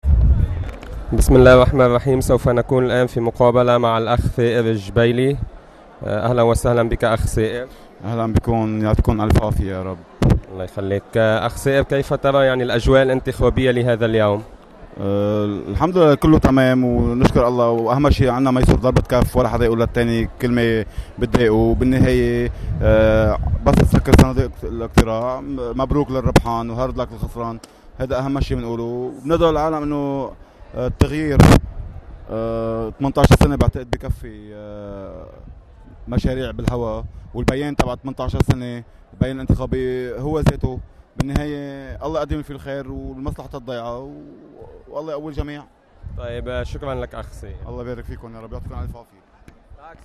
مقابلة